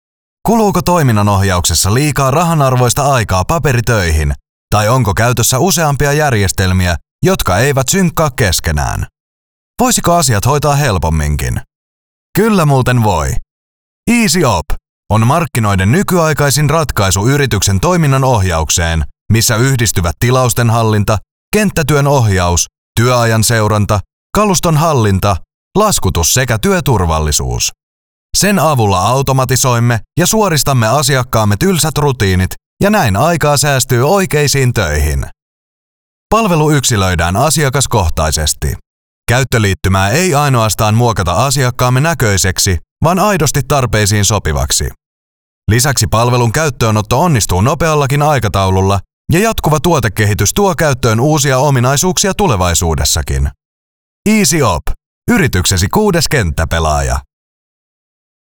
Junge, Cool, Corporate
Unternehmensvideo